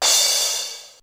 DMX CRASH.wav